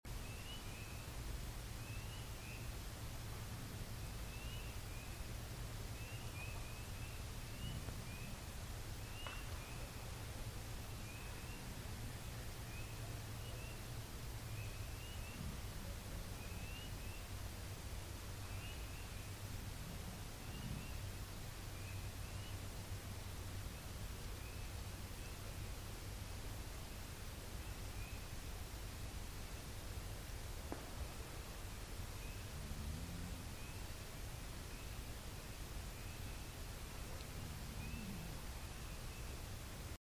These were recorded from Dropbox #1 and various other team member recorders:
woodknocks_19.mp3